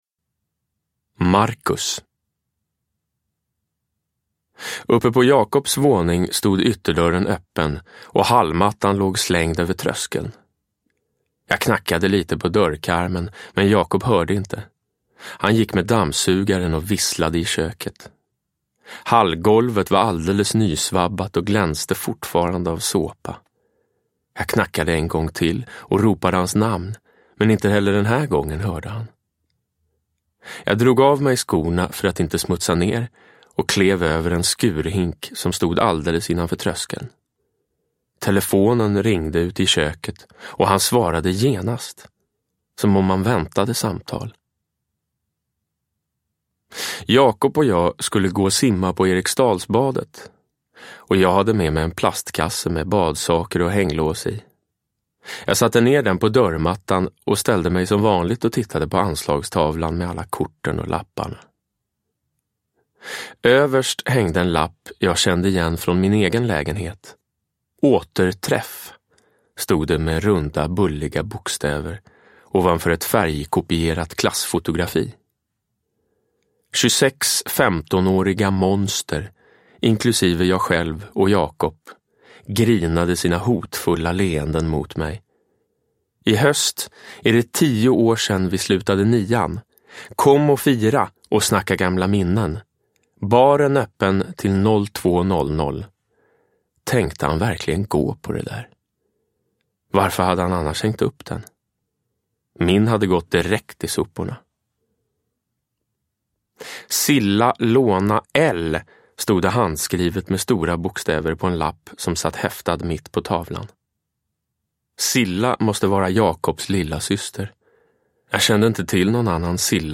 Med ett absolut gehör i dialogerna och en blick för de små detaljerna läser Jonas Karlsson skruvat, roligt, dråpligt, drivet.
Uppläsare: Jonas Karlsson